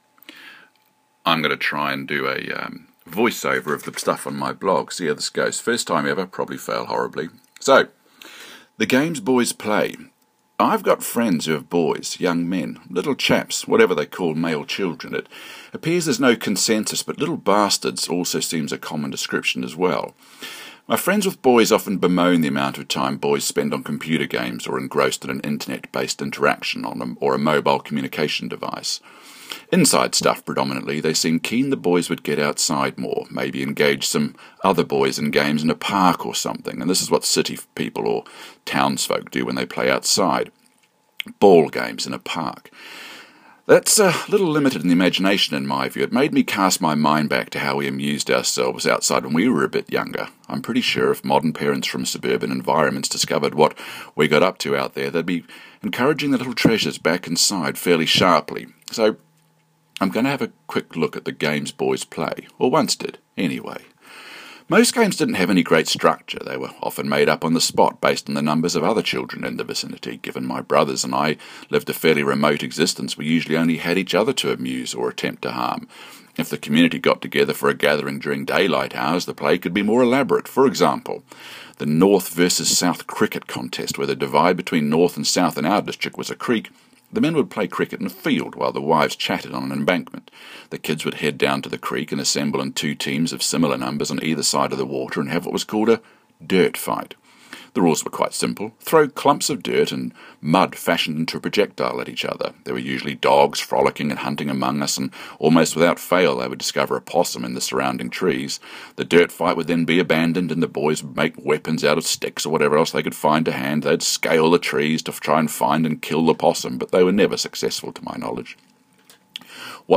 If you prefer to hear things being read out rather than reading it.